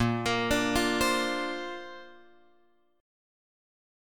A#add9 chord {6 x 8 7 6 8} chord